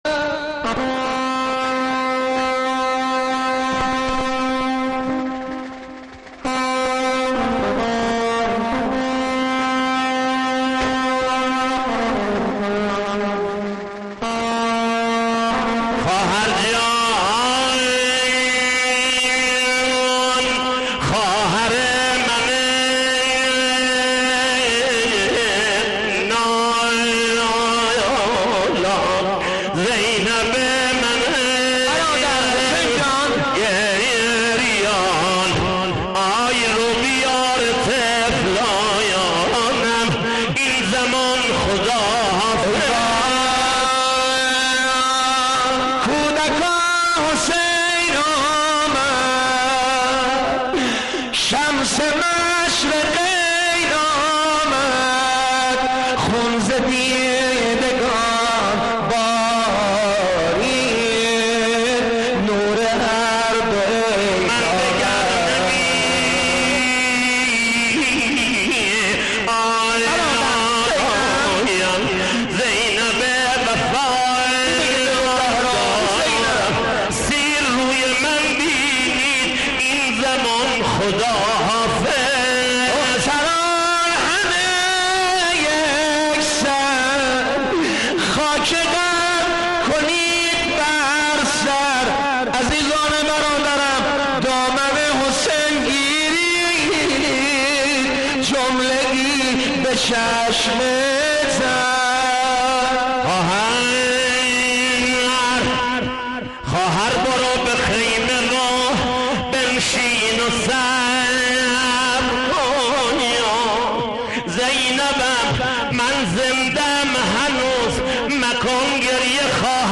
تعزیه امام حسین 90 - وداع
تعزیه قودجان